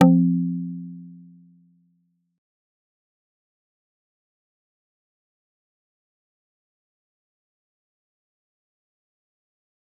G_Kalimba-F3-mf.wav